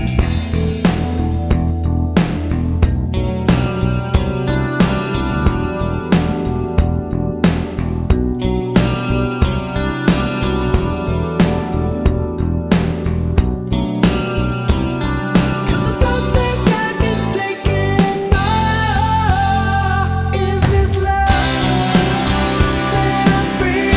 No Guitar Solo Rock 4:21 Buy £1.50